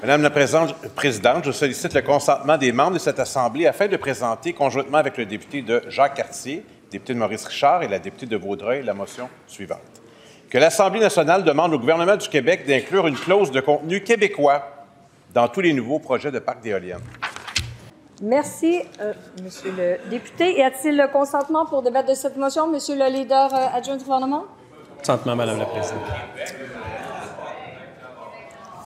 Hier, à l’Assemblée nationale, le député péquiste de Matane-Matapédia, Pascal Bérubé, a déposé une motion en ce sens, et le gouvernement a simplement refusé d’en débattre.